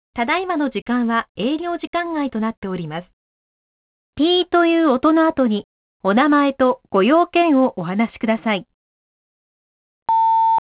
【留守番電話アナウンス一覧】
■留守番電話５